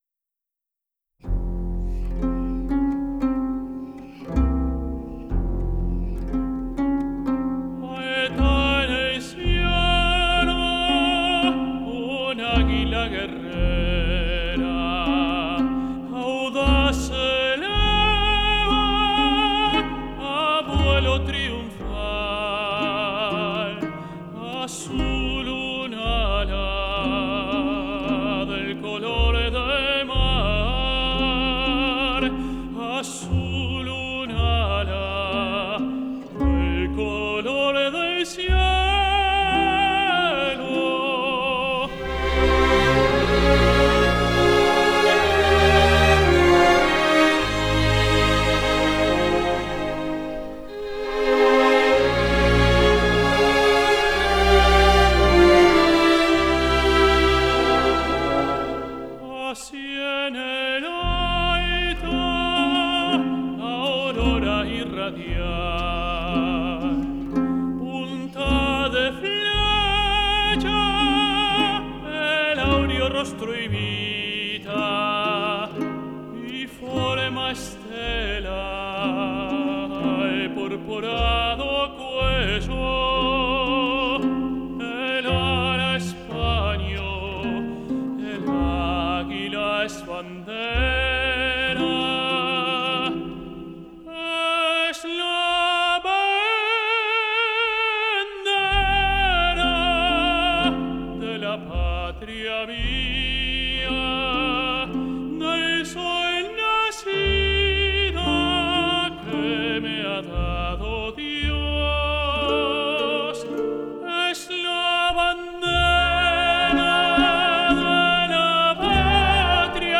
Aria de la ópera de igual nombre
tenor